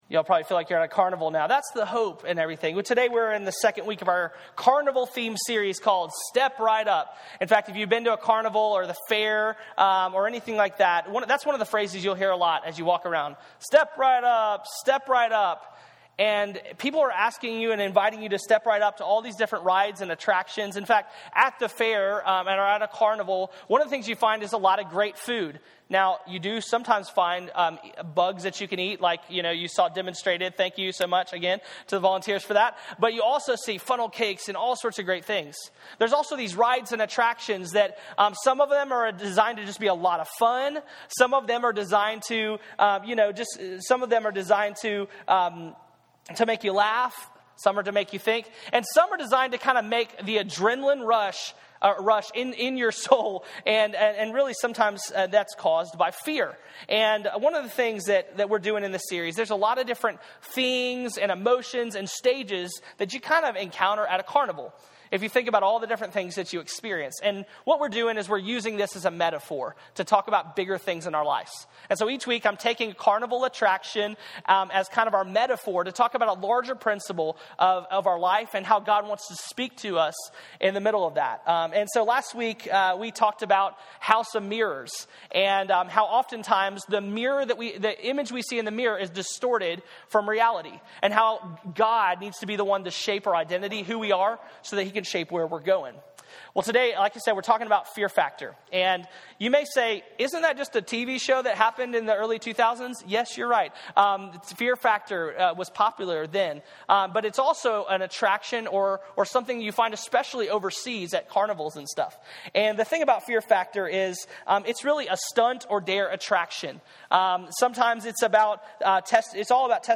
SERMON SERIES Step Right Up At the carnival, people are invited to "Step Right Up" to experience amusing, terrifying, and fascinating things.